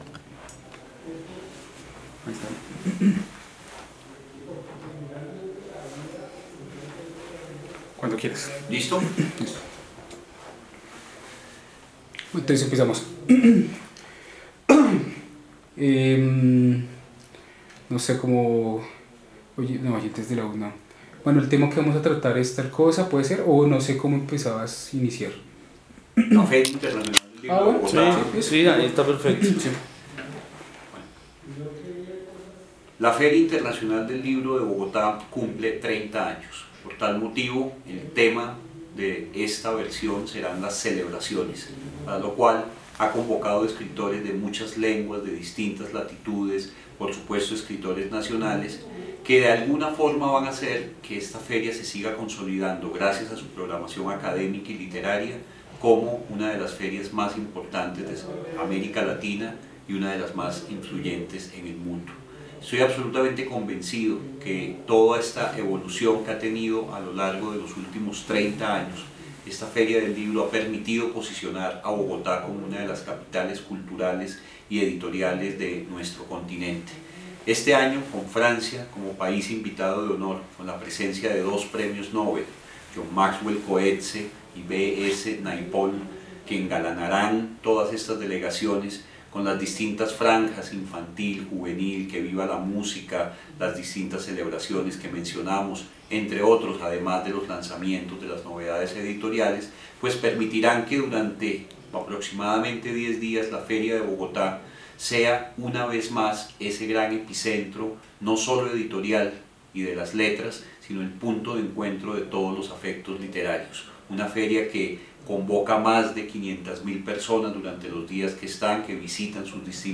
Programas de radio